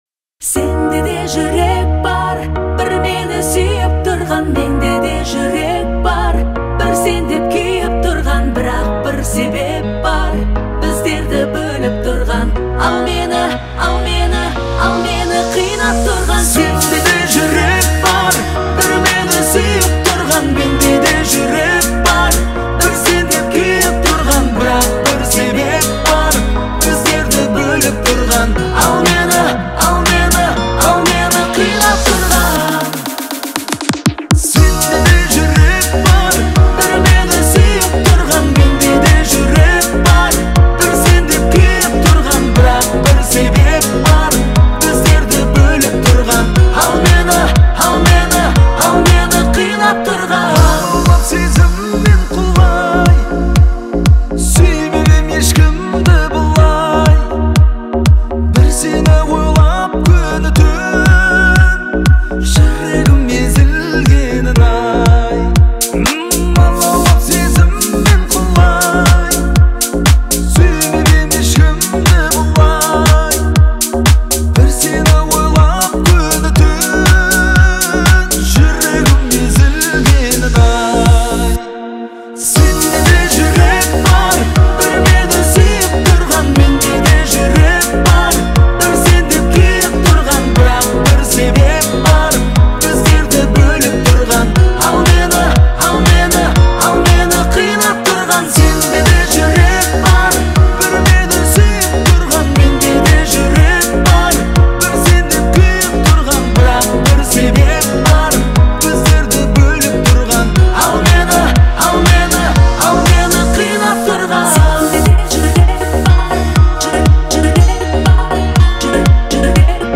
(remix)